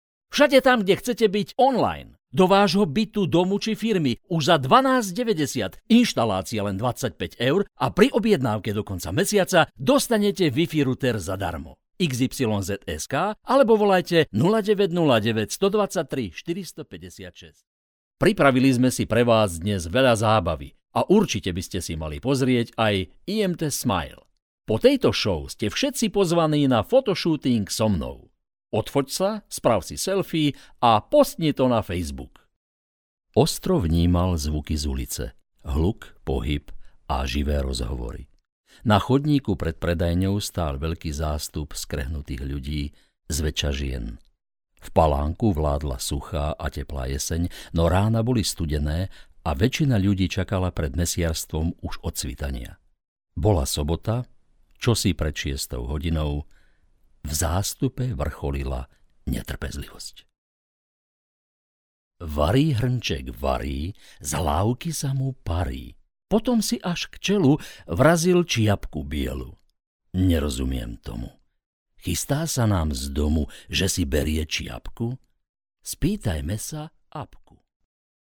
Professionelle Sprecher und Sprecherinnen
Weiblich